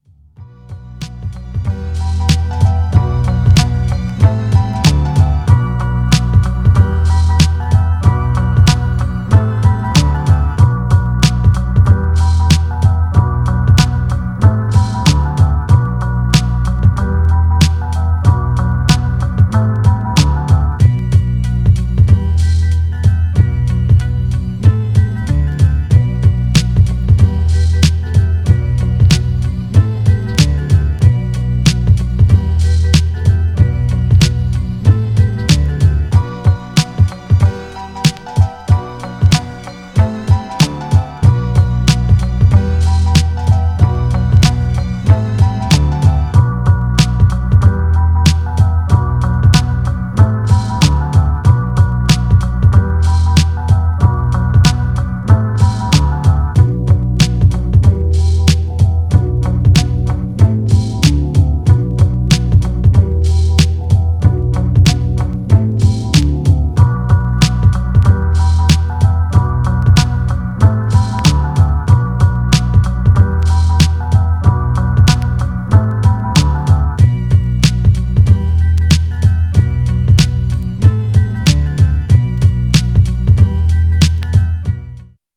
Styl: Hip Hop, Lounge